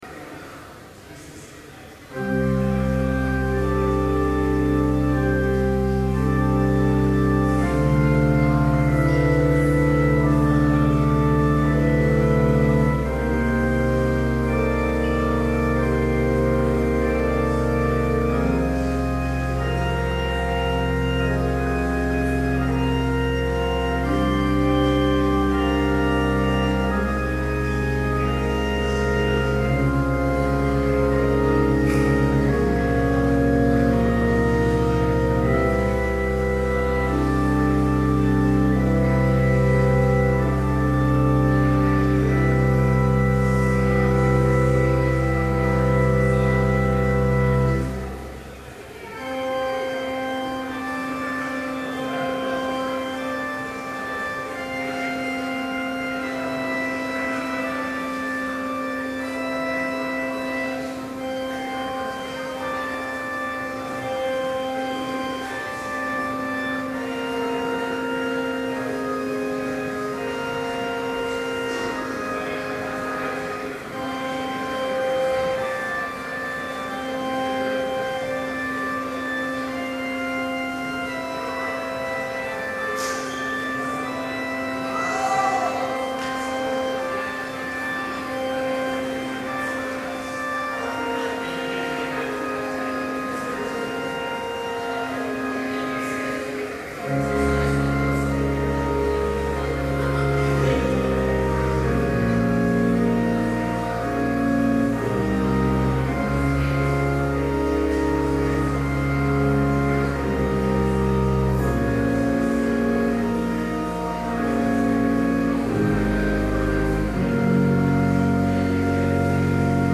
Complete service audio for Chapel - May 2, 2012